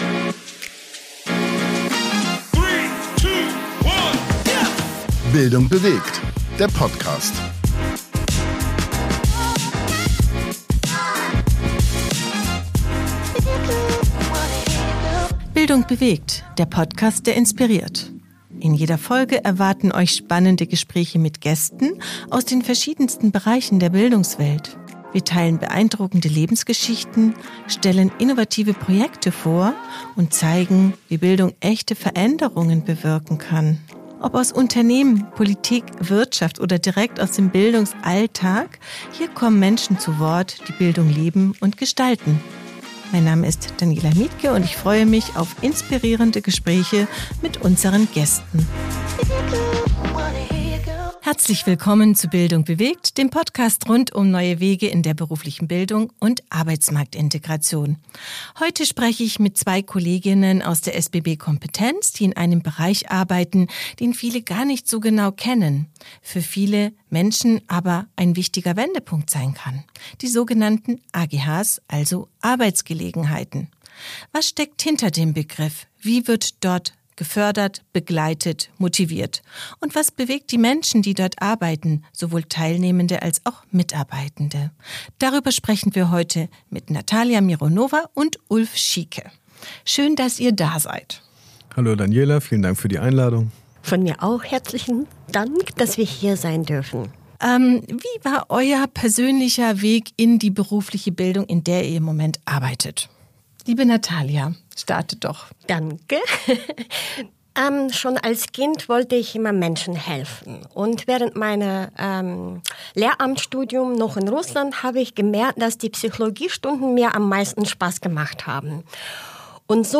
Es geht um individuelle Begleitung, neue Impulse und persönliche Erfolgsgeschichten, die Mut machen. Offen und praxisnah sprechen die Beteiligten über Herausforderungen, Erfahrungen und die oft unterschätzte gesellschaftliche Bedeutung ihrer Arbeit.